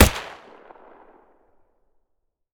SingleShot.wav